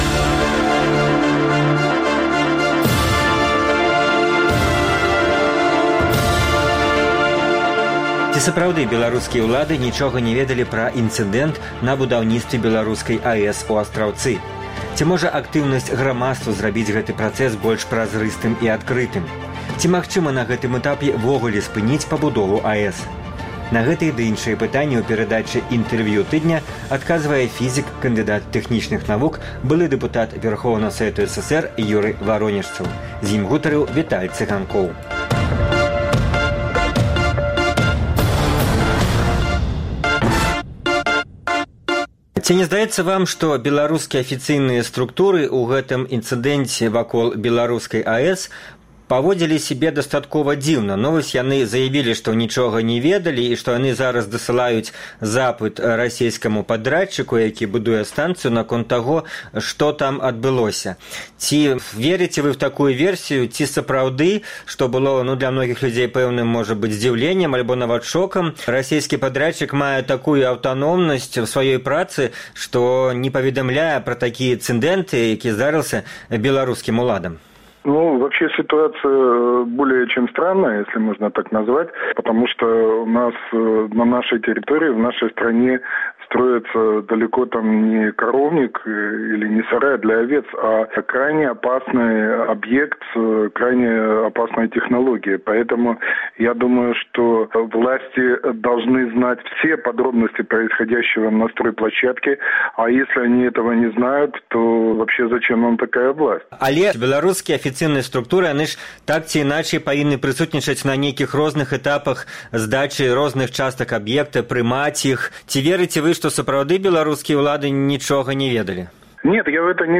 Ці магчыма на гэтым этапе ўвогуле спыніць пабудову АЭС? На гэтыя ды іншыя пытаньні ў перадачы “Інтэрвію тыдня” адказвае фізык, кандыдат тэхнічных навук, былы дэпутат Вярхоўнага Савету СССР Юры Варонежцаў.